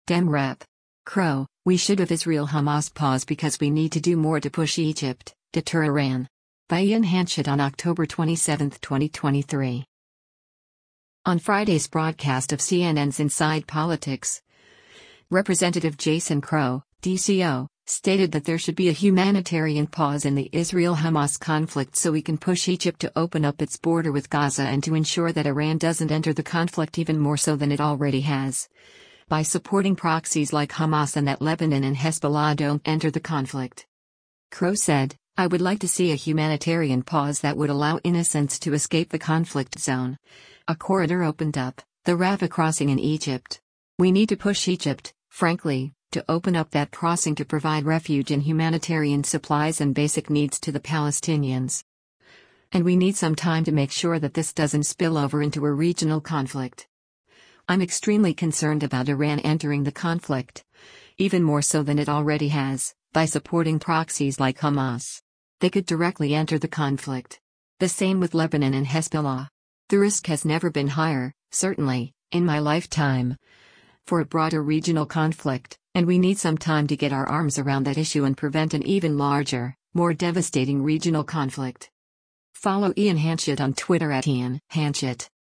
On Friday’s broadcast of CNN’s “Inside Politics,” Rep. Jason Crow (D-CO) stated that there should be a “humanitarian pause” in the Israel-Hamas conflict so we can push Egypt to open up its border with Gaza and to ensure that Iran doesn’t enter the conflict “even more so than it already has, by supporting proxies like Hamas” and that Lebanon and Hezbollah don’t enter the conflict.